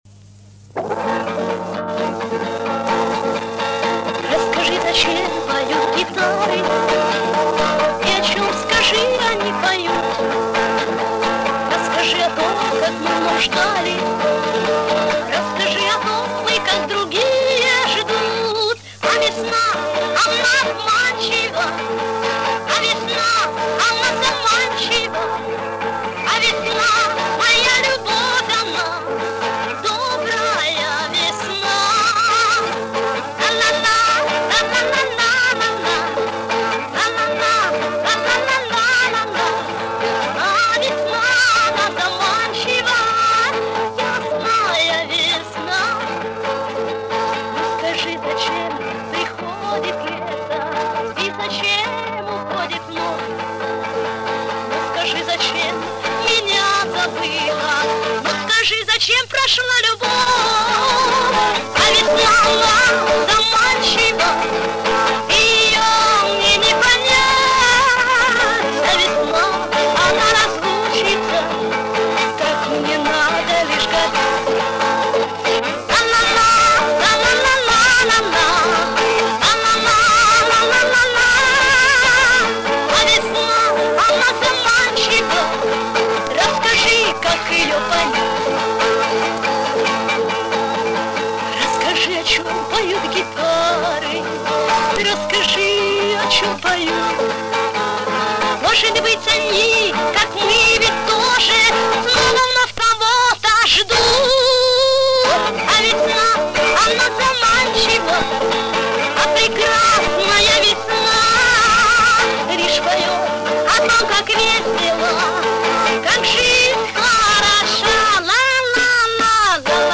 Дворовые Песни Шансона